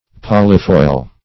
Polyfoil \Pol"y*foil\, n.